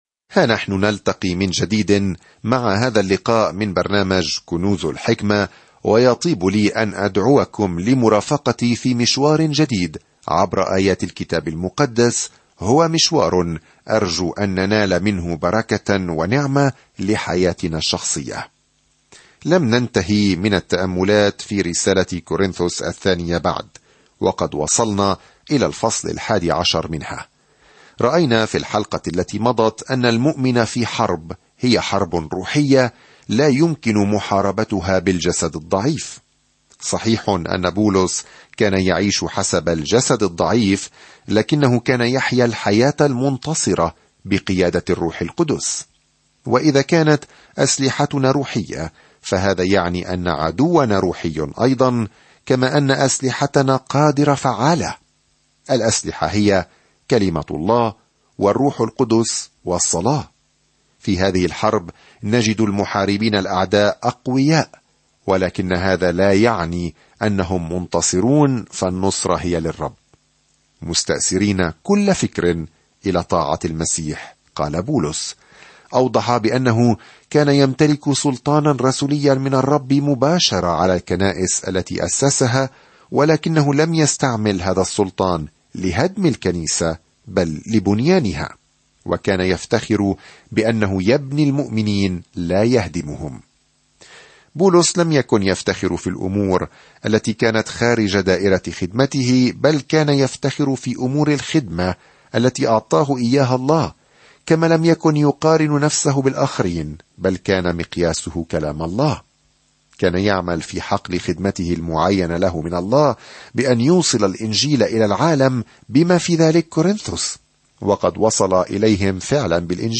الدراسة الصوتية